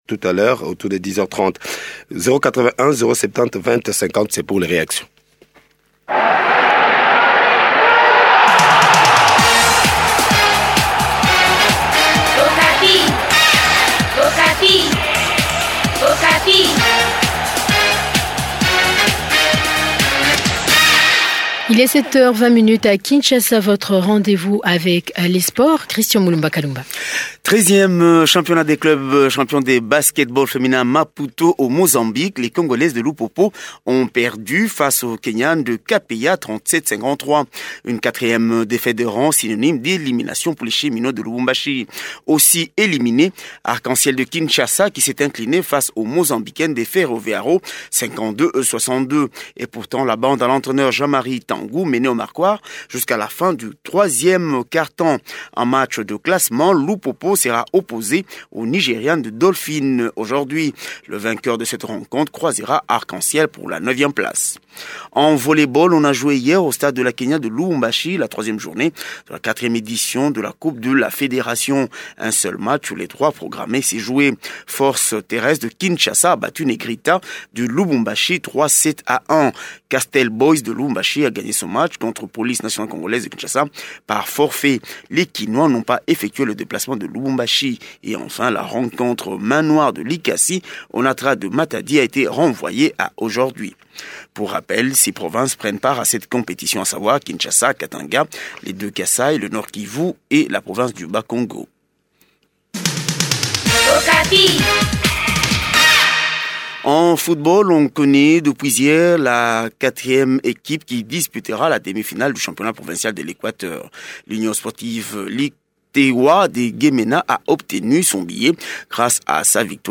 Journal des Sports